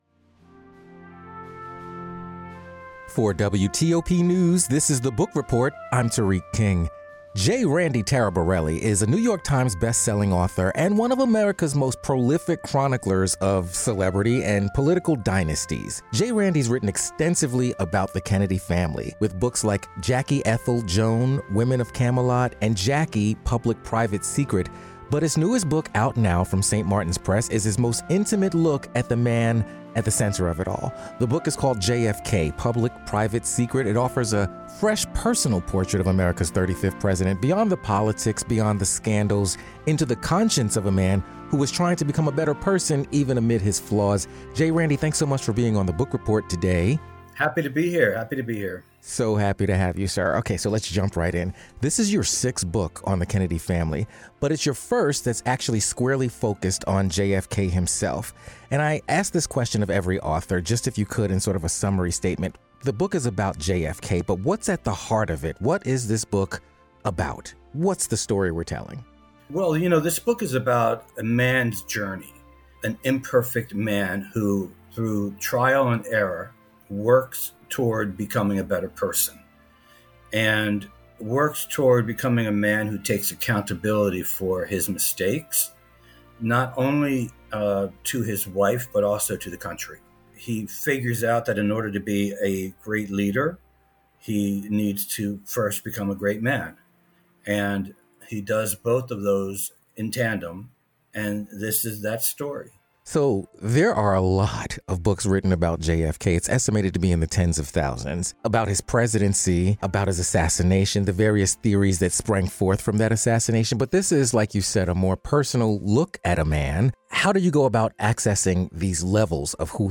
J. Randy Taraborrelli discusses 'JFK: Public, Private, Secret" with the WTOP Book Report.